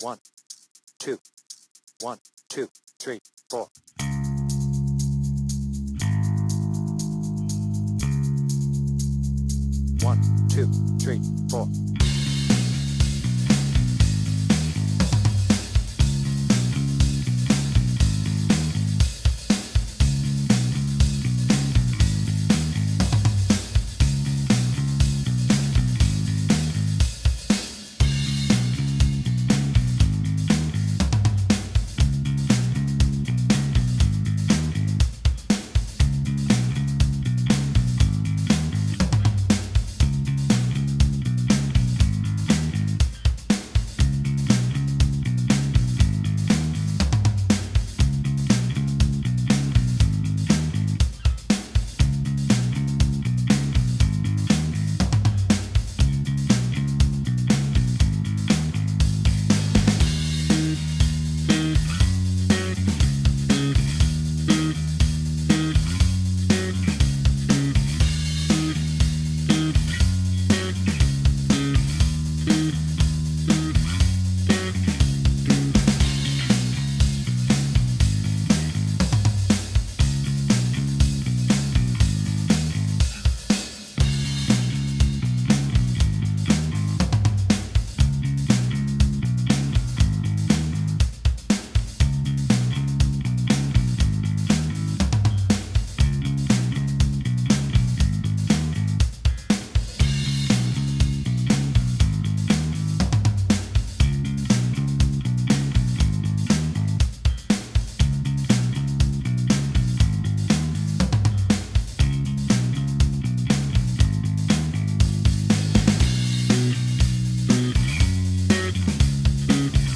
BPM : 120
Tuning : Eb
Without vocals